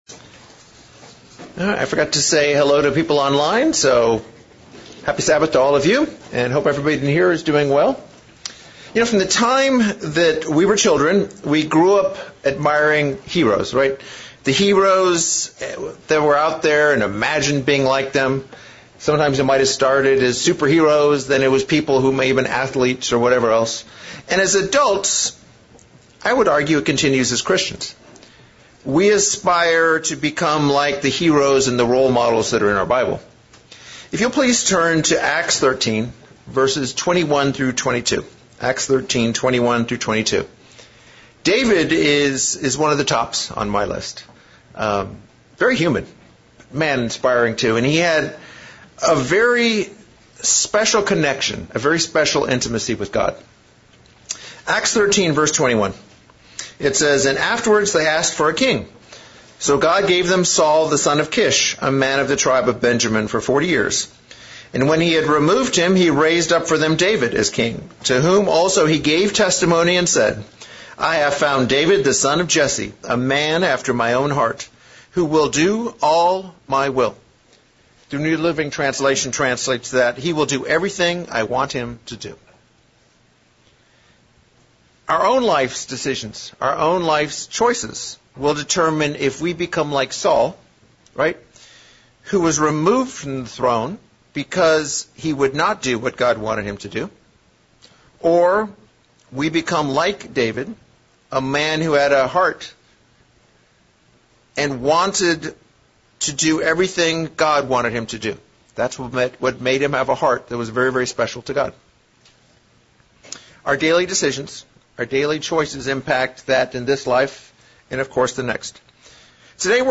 What made David a man after God's own Heart? It started with his desire for God to teach him. 15 times in the Psalms David asks God, "Teach Me". This sermon reviews these verses and parallels how we can make the same requests of God to please him in the same way.